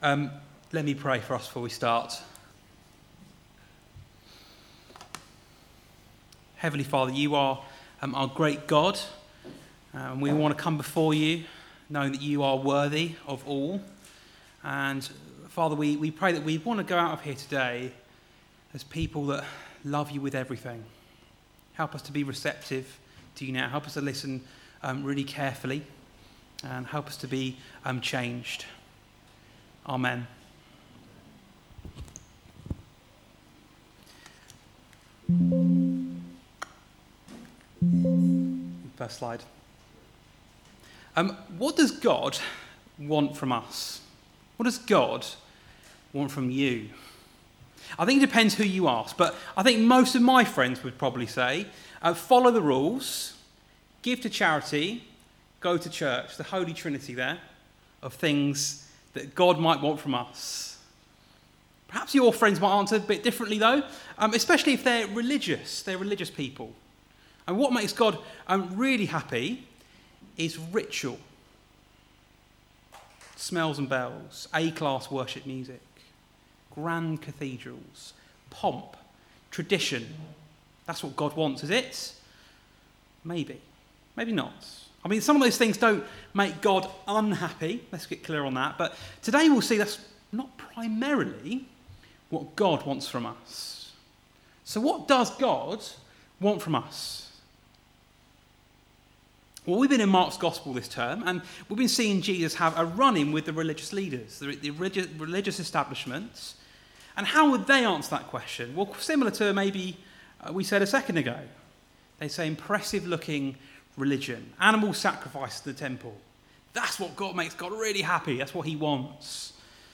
Passage: Mark 12:28-44 Service Type: Weekly Service at 4pm